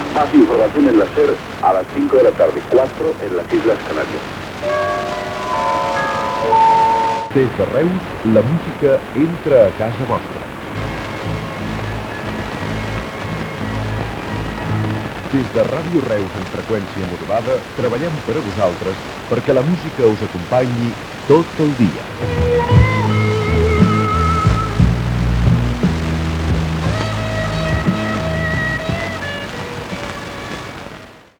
Qualitat de l'àudio baixa